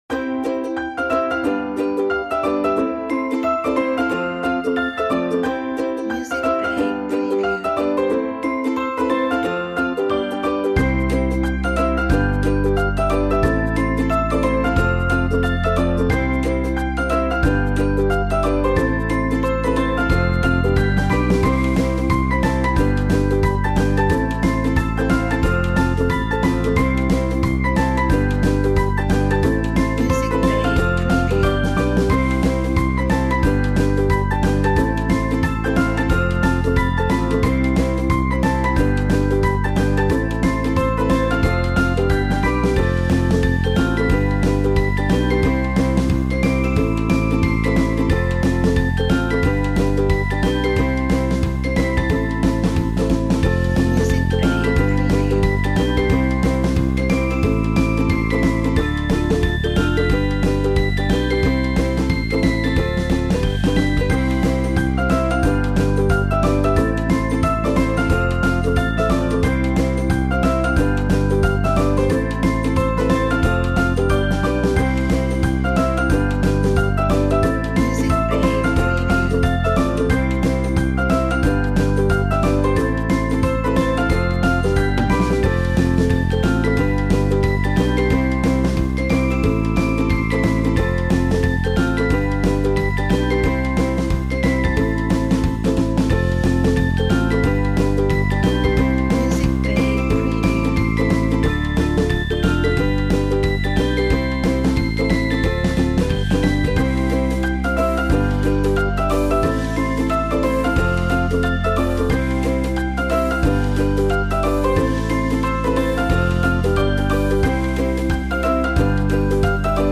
all happy and joyful background music for videos